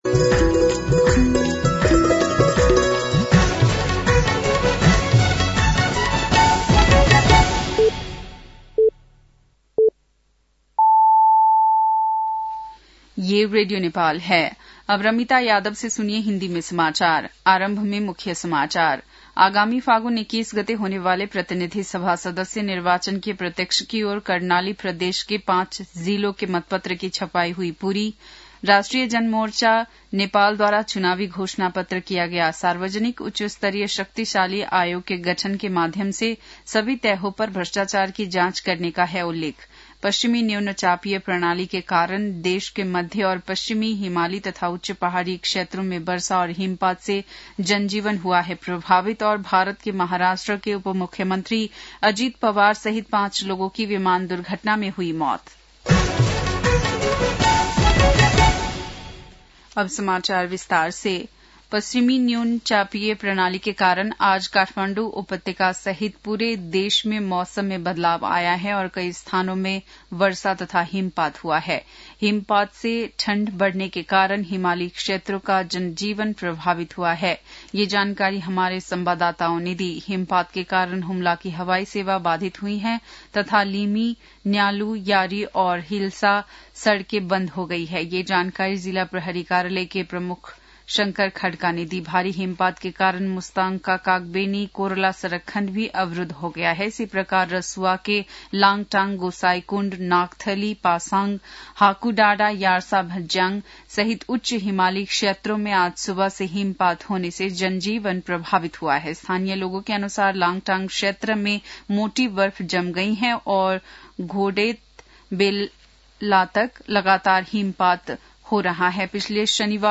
बेलुकी १० बजेको हिन्दी समाचार : १४ माघ , २०८२